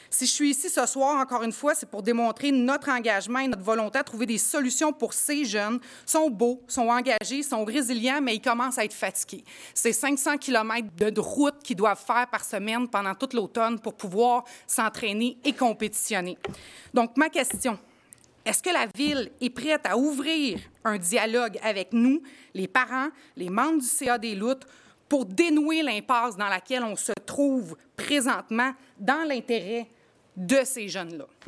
Lors de la séance du conseil de Ville de Granby lundi soir, plusieurs parents et athlètes du défunt club de natation Les Loutres étaient présents pour remettre une pétition, demandant à la Ville de revenir sur sa décision ou à tout le moins de trouver une solution pour permettre à leurs enfants de profiter des installations du Centre aquatique Desjardins.